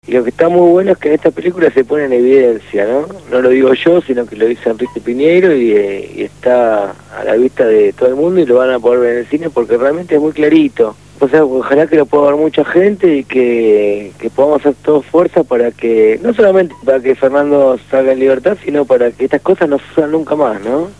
Hoy fue entrevistado